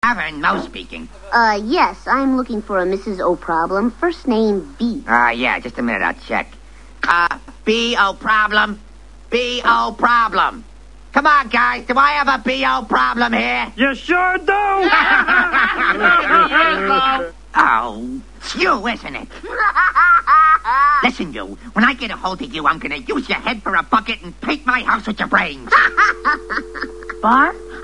Prank Call (162KB)